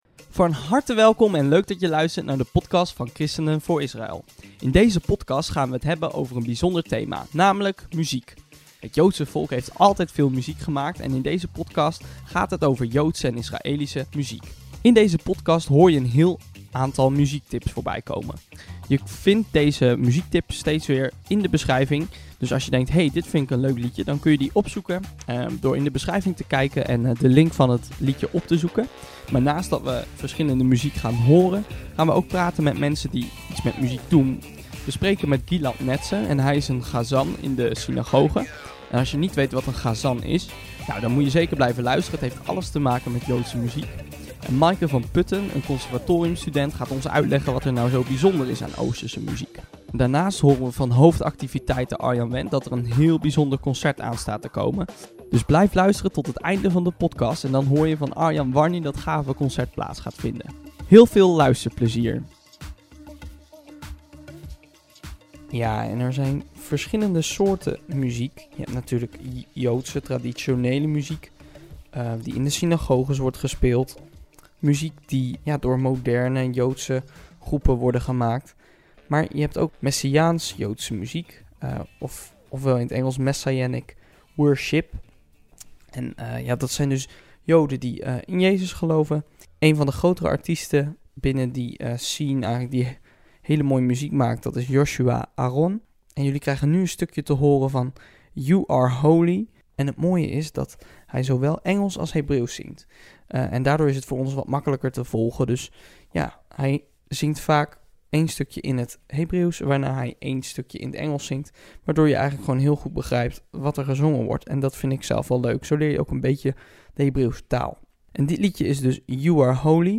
In deze podcast hoor je de mooiste Hebreeuwse en Israëlische muziek! Je krijgt een aantal muziektips van ons die je niet wilt missen.